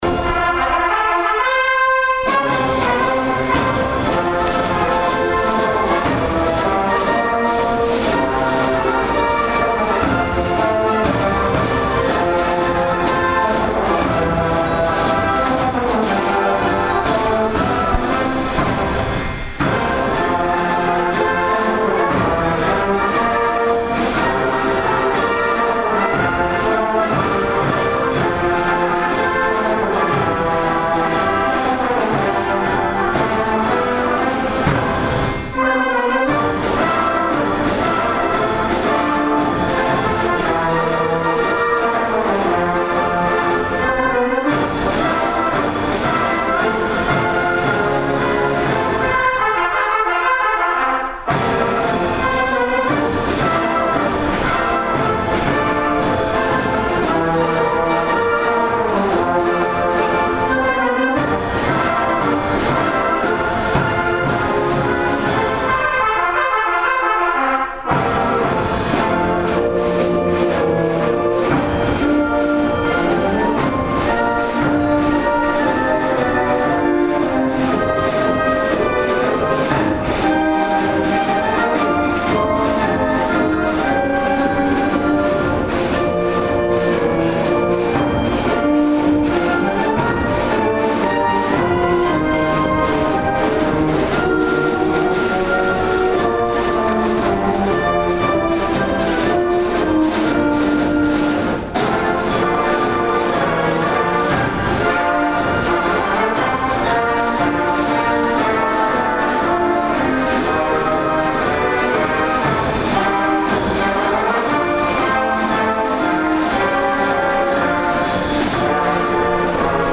The third and final indoor concert for the 2025 season by the Karl L. King Municipal Band was held on Sunday afternoon, April 27th at 3:30 p.m. in the Fort Dodge Middle School Auditorium.
The three students who auditioned were invited to join the Band in playing Karl L. King’s march, The Iowa Band Law.